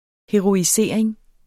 Udtale [ heʁoiˈseˀeŋ ]